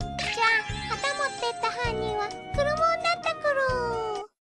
That said, enjoy Terriermon's cute voice!
Terriermon imitating Culumon